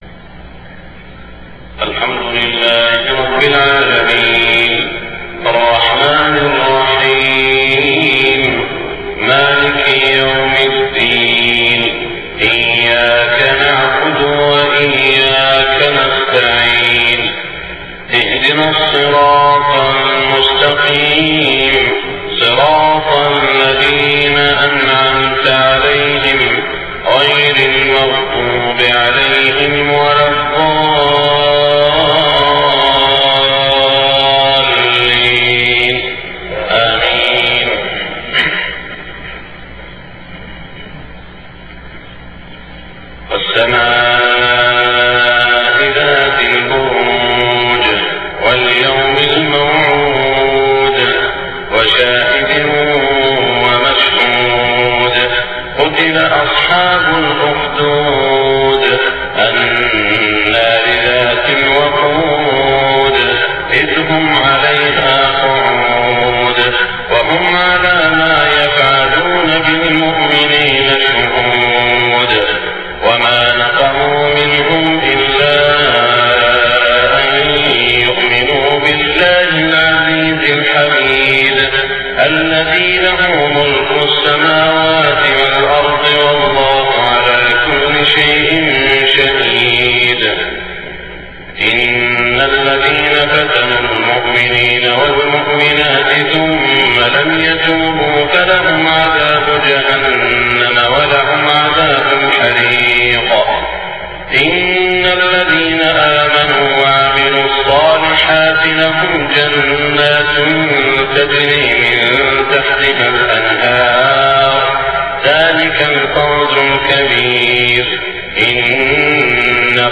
صلاة الفجر 7-4-1425هـ سورتي البروج و الغاشية > 1425 🕋 > الفروض - تلاوات الحرمين